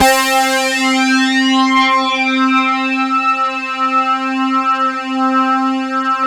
SYN FAT   02.wav